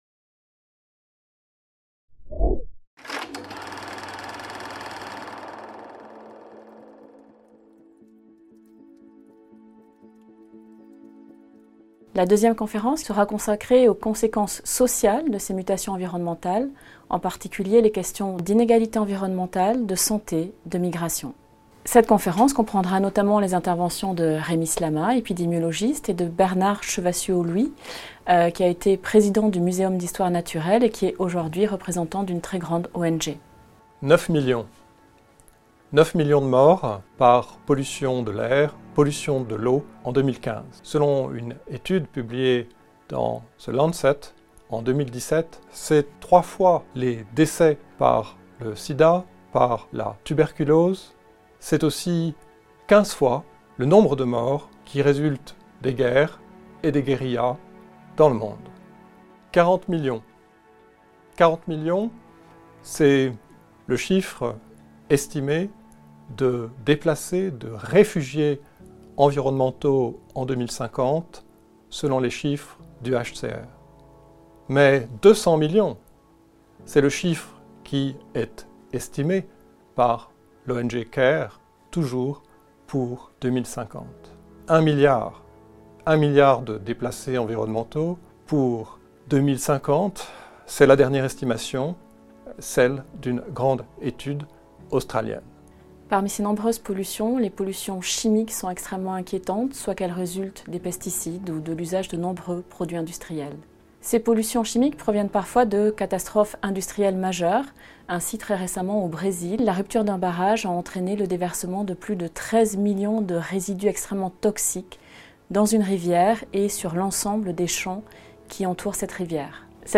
Programme 2020/2021 du cycle bi-annuel de conférences à la Cour de cassation